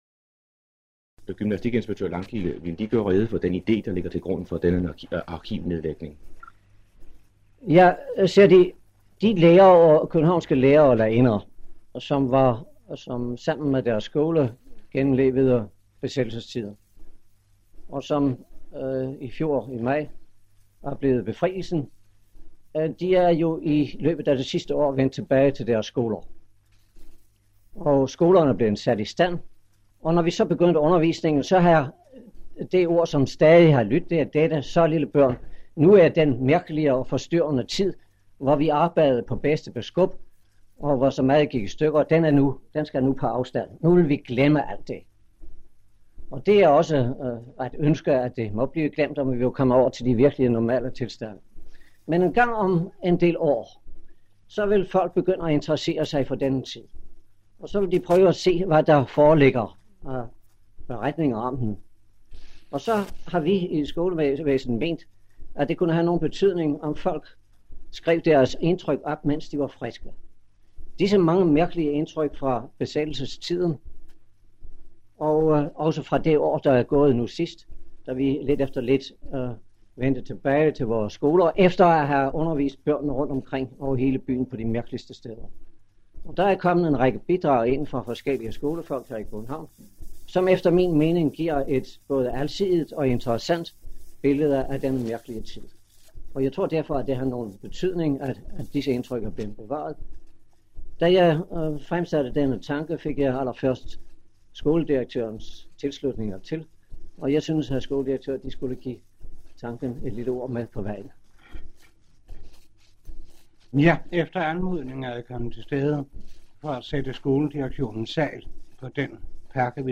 Samtale
ved overdragelsen af københavnske skolefolks beretninger om besættelsen 1940-1946